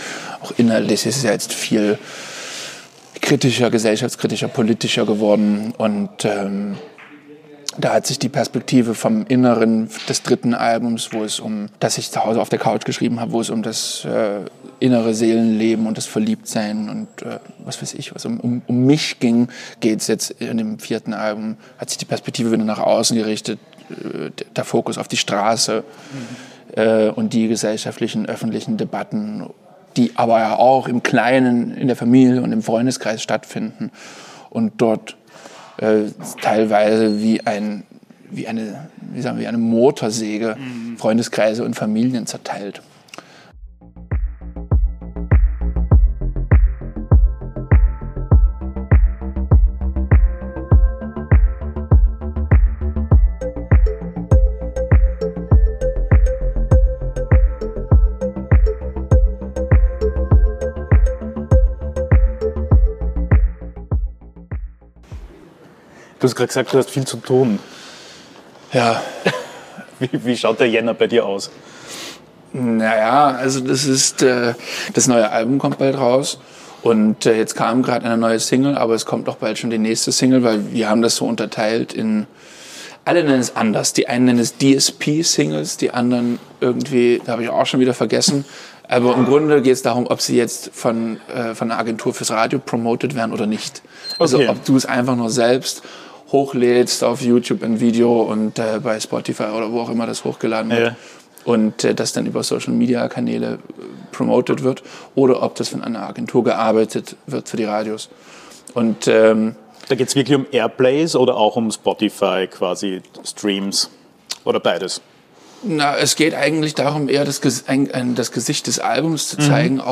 Aufgenommen wurde diese Folge von “Was soll das alles?” im Wiener Café Weidinger, die Hintergrundgeräusche gehören also diesmal bewusst dazu.